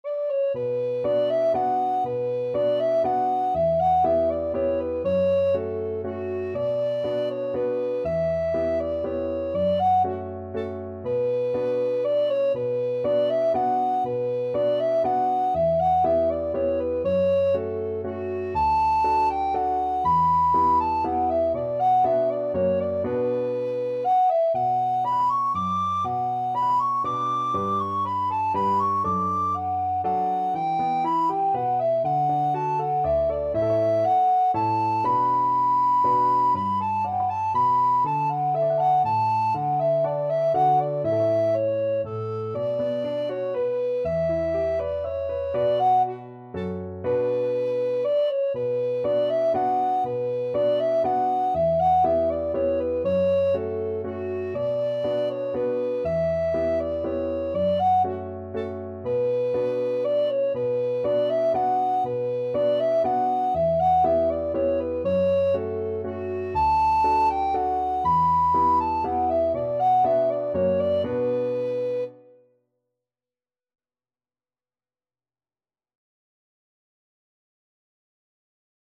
= 120 Allegro (View more music marked Allegro)
3/4 (View more 3/4 Music)
Traditional (View more Traditional Alto Recorder Music)